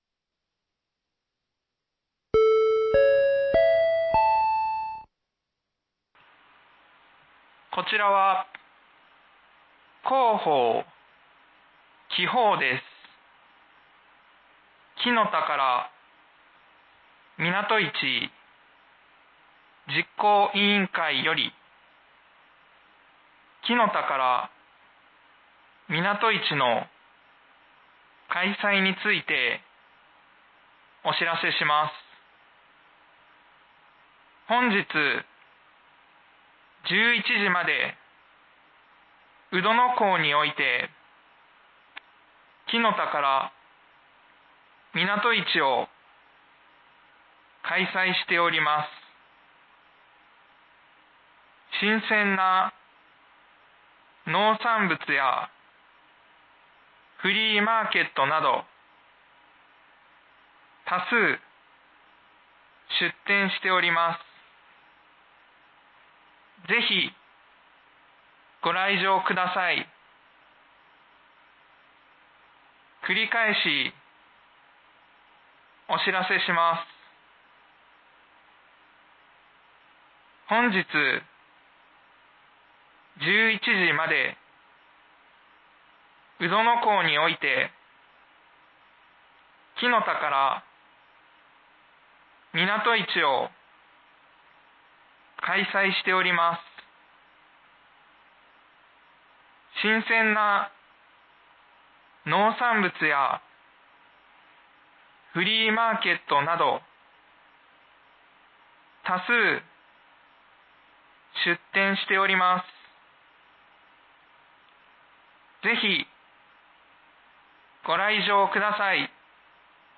紀宝町防災無線情報
放送音声